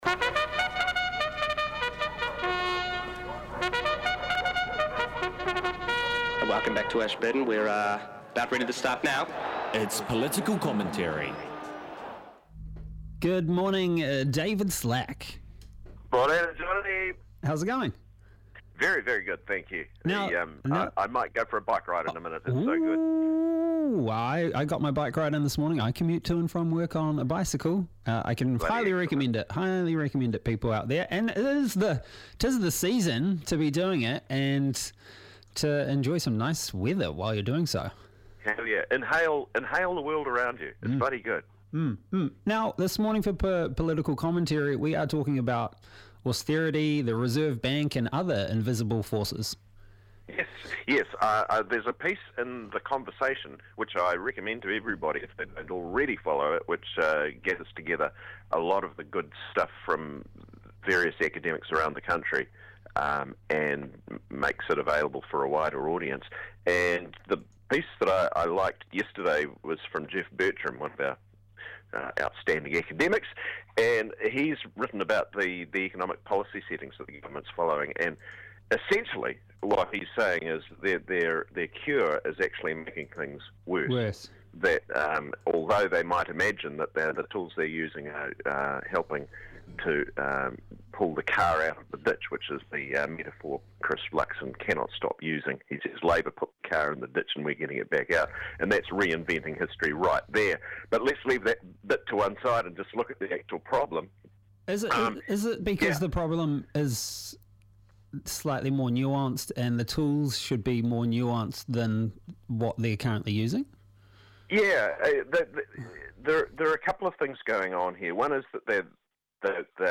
Political Commentary